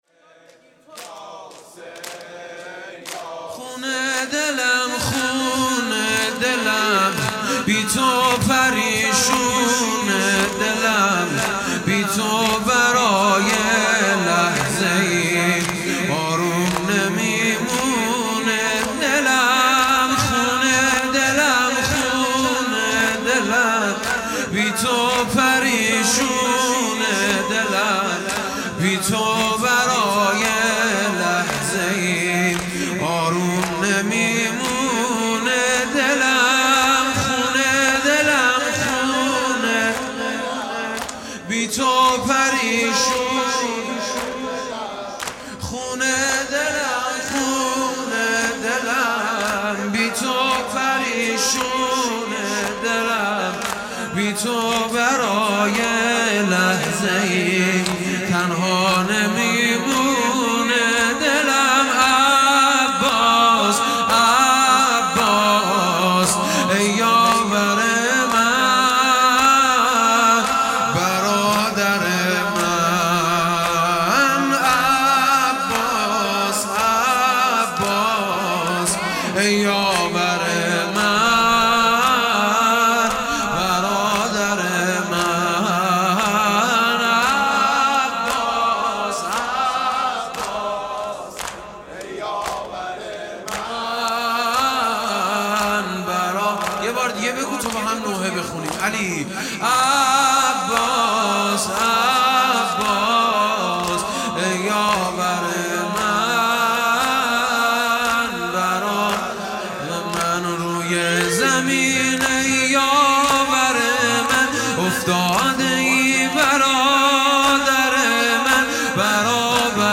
خونه دلم خونه دلم|جلسه هفتگی